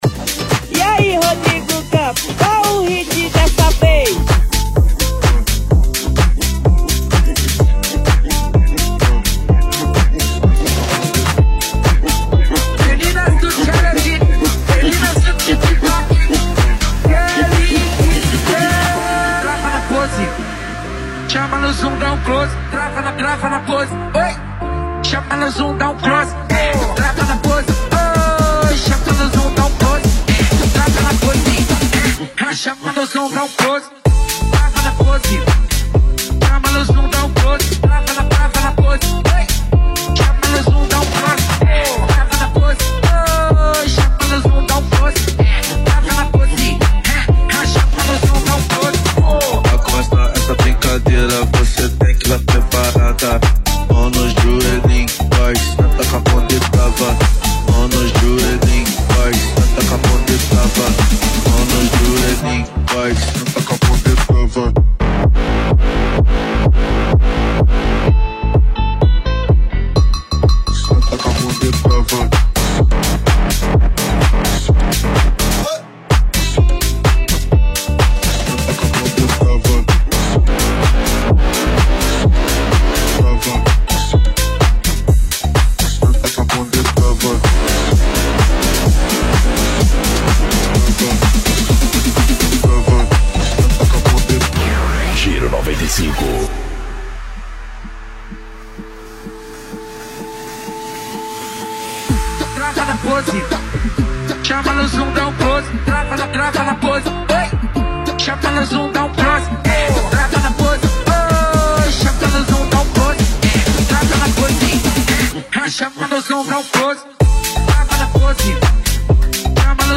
Uma batida “quente” para aqucer seu fim de semana